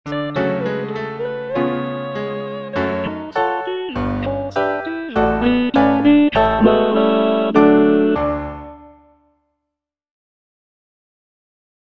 G. BIZET - CHOIRS FROM "CARMEN" Répondez, camarade (bass I) (Emphasised voice and other voices) Ads stop: auto-stop